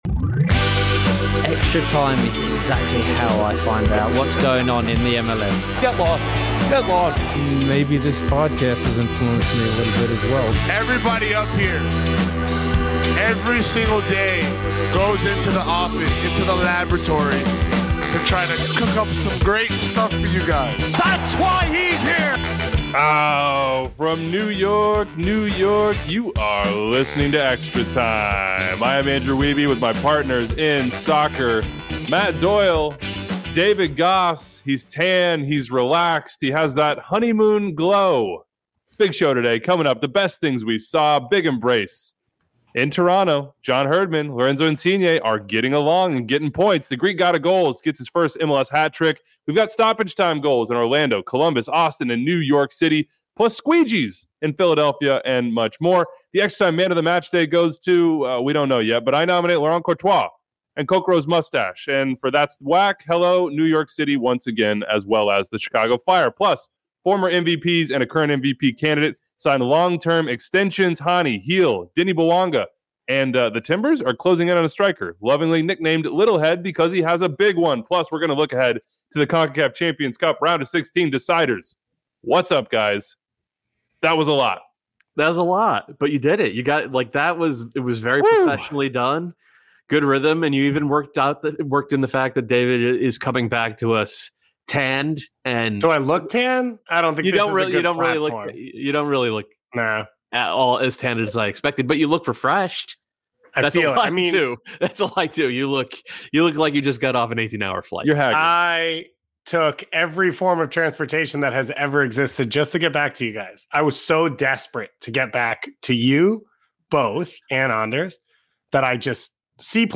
It's back to a 3-man show